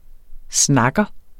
Udtale [ ˈsnɑgʌ ]